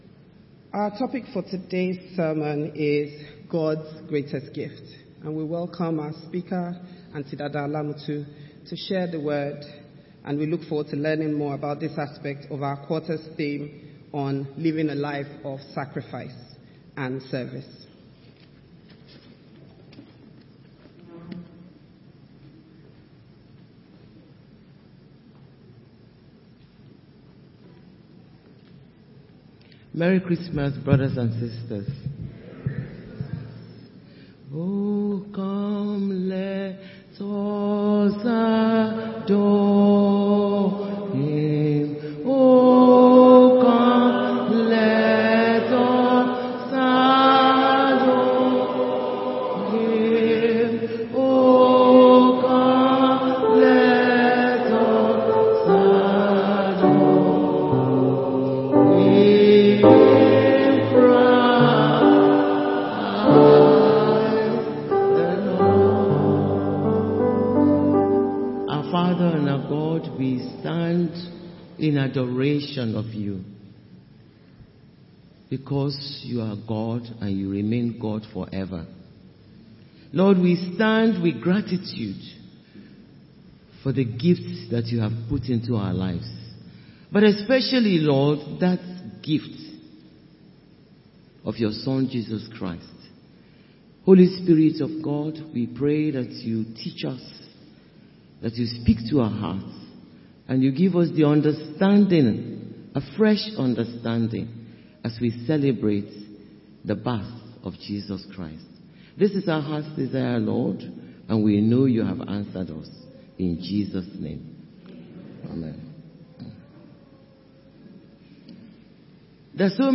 Christmas Service – Victoria Fellowship Church